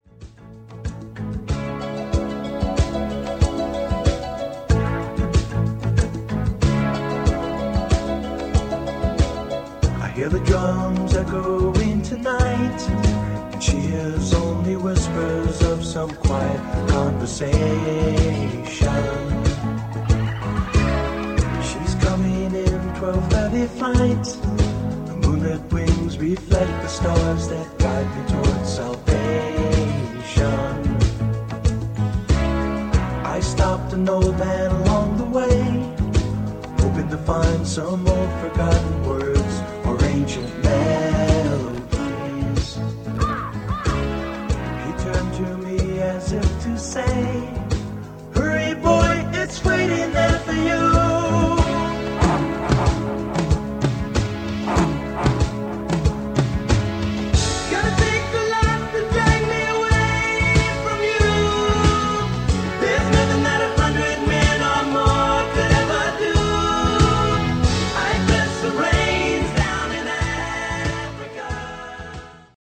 Below is a test recoding made with the RS-TR165 and played back by it:
Technics-RS-TR165-Test-Recording.mp3